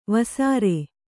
♪ vasāre